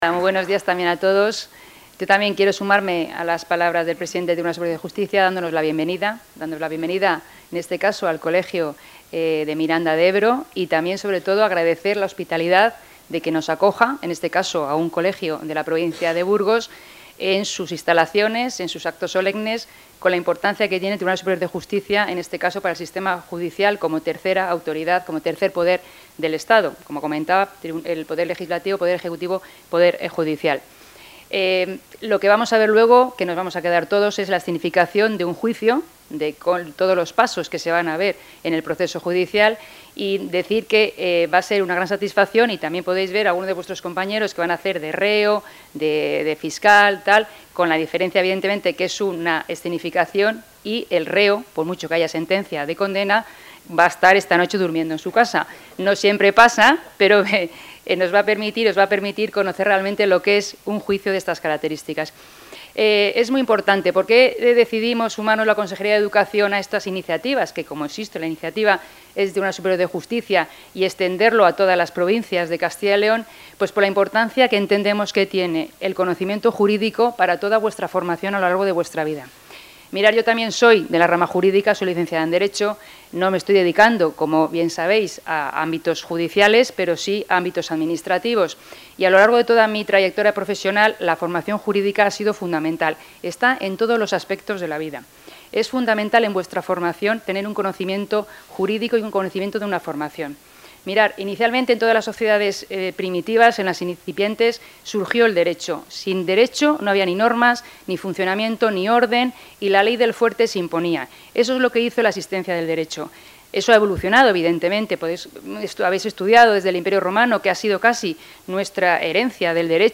Intervención de la consejera de Educación.